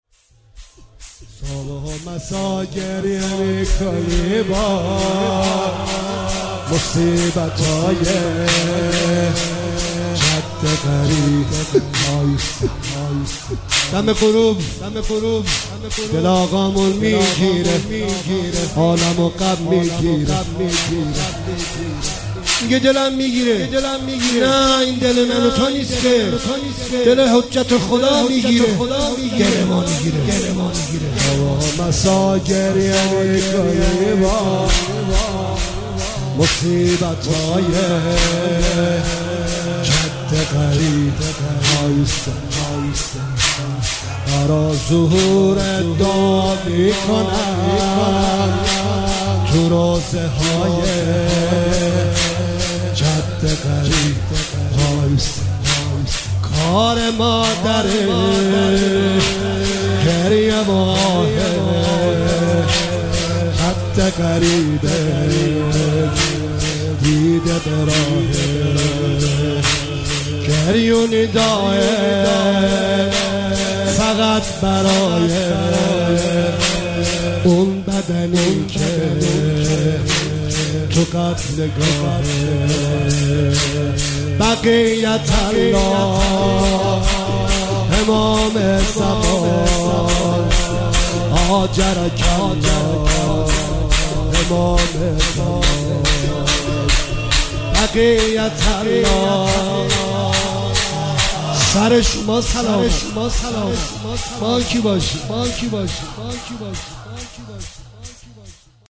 مداحی شور شب اول محرم